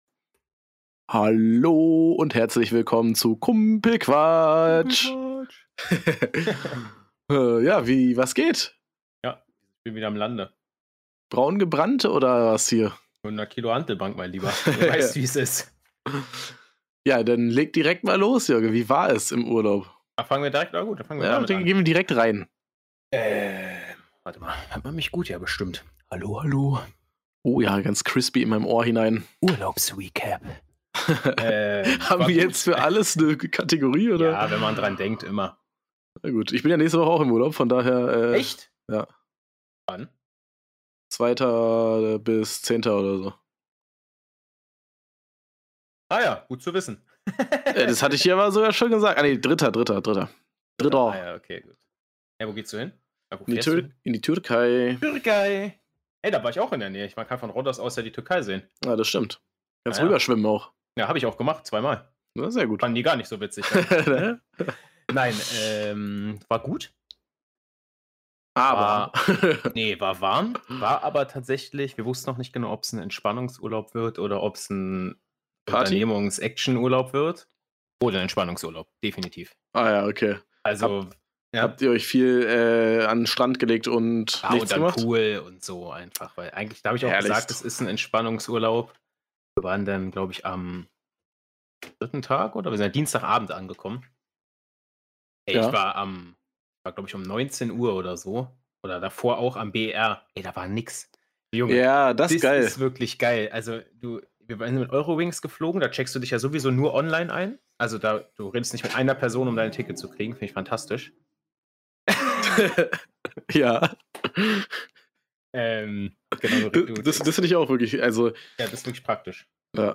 Ganz naturgemäß starten die beiden Podcast Hosts in den Podcast. Weiter gehts mit Urlaubsfeelings und zum Ende hin wirds nochmal richtig lustig.